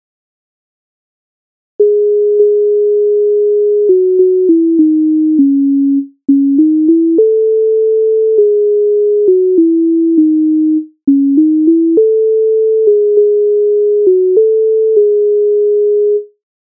MIDI файл завантажено в тональності cis-moll